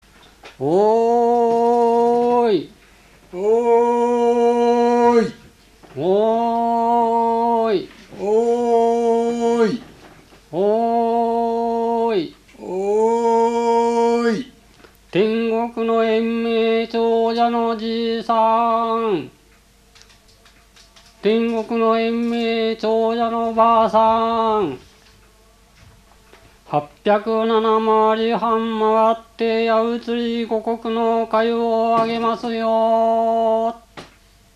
104 75 8 鴨川市 　 南小町
祝詞家移り 祝い歌